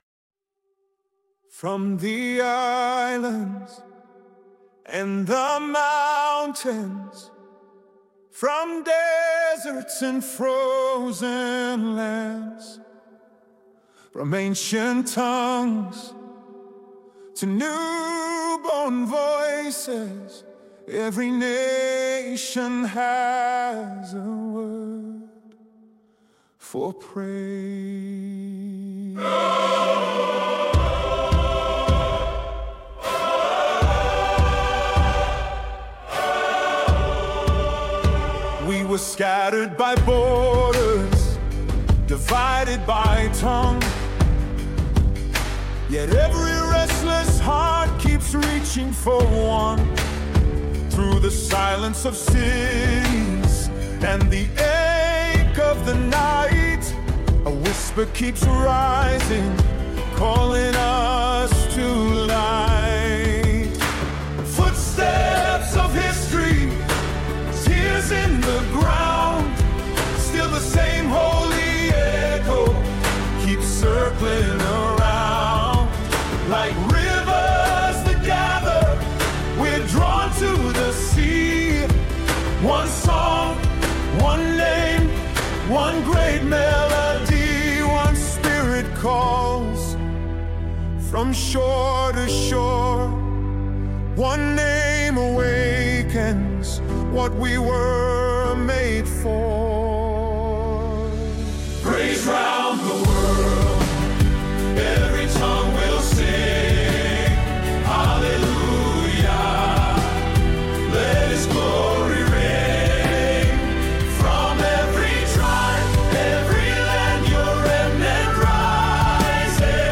Original worship music including
[ Practice Track – Lower Key ]